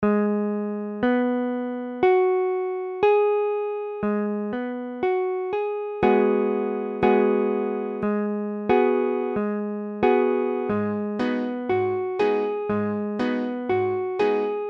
Illustration sonore : IV_Abm7.mp3
IV_Abm7.mp3